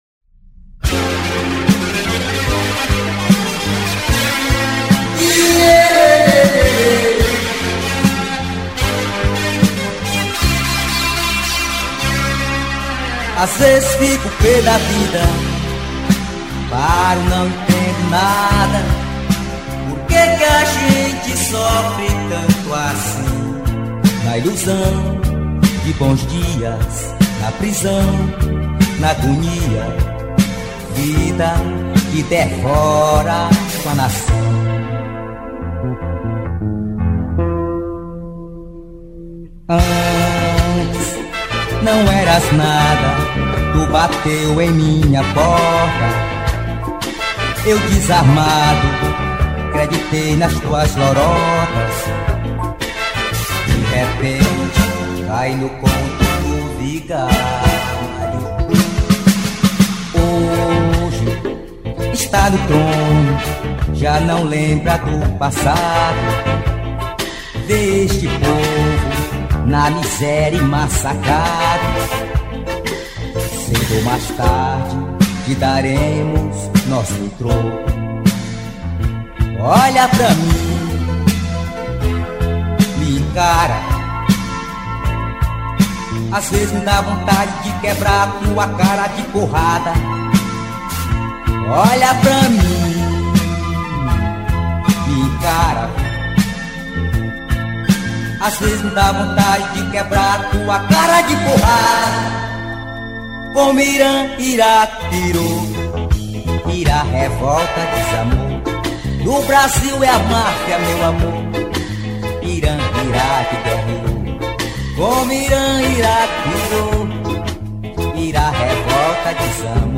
2633   02:52:00   Faixa: 6    Rock Nacional